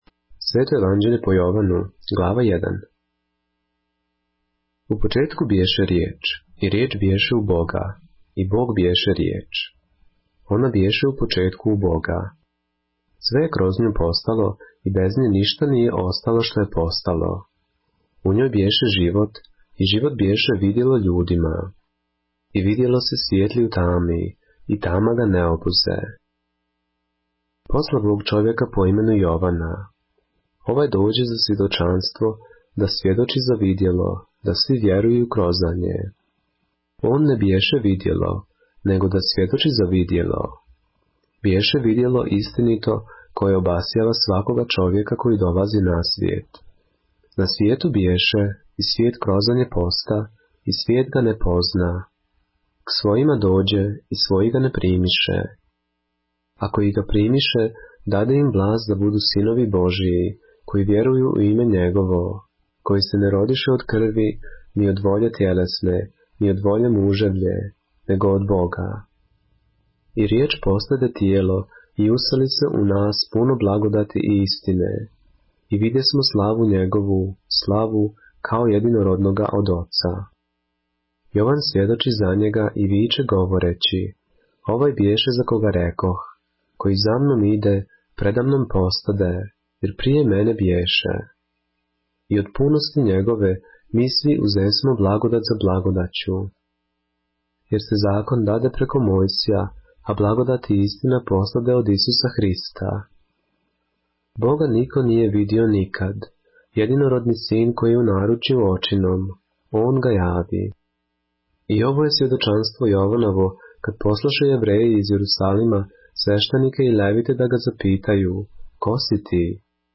поглавље српске Библије - са аудио нарације - Христос Реч која је постала тело - Jovanu - ЈОВАН 1